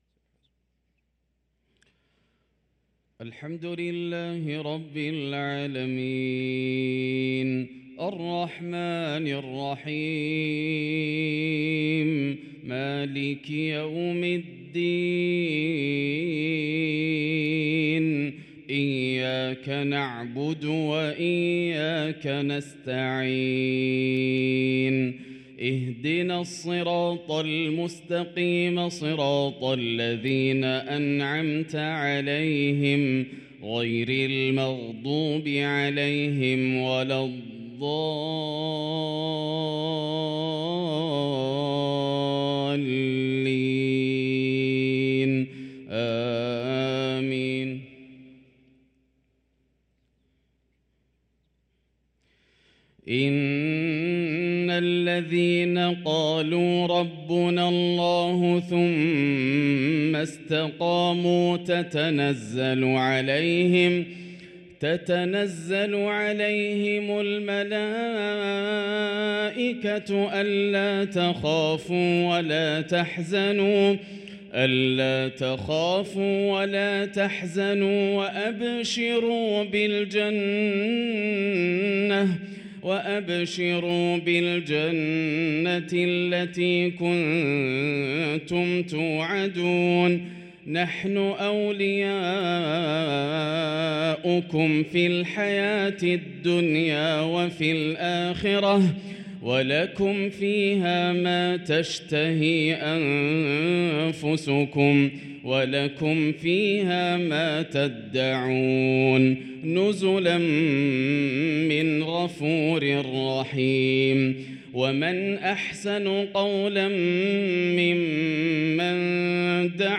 صلاة العشاء للقارئ ياسر الدوسري 20 صفر 1445 هـ